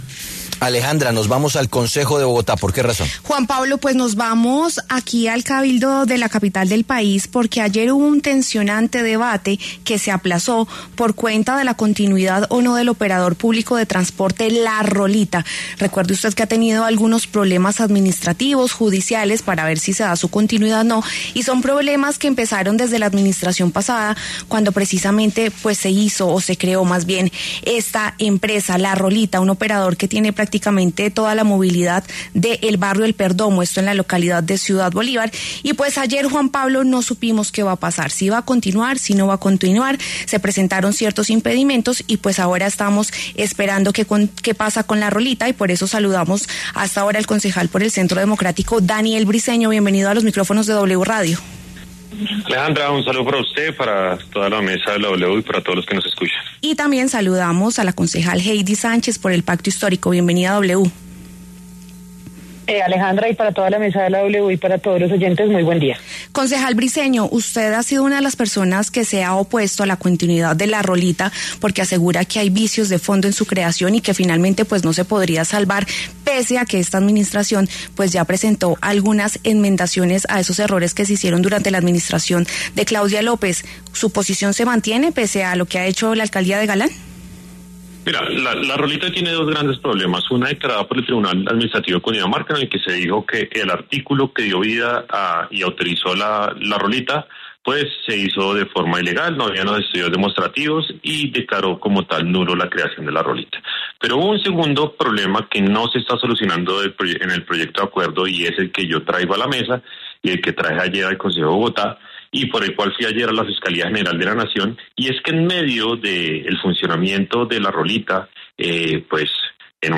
Ante esto, el concejal Briceño aseguró en los micrófonos de La W Radio que ‘La Rolita’ tiene dos problemas y que la Alcaldía de Bogotá no ha resuelto uno de ellos: la venta de acciones a Enel Codensa.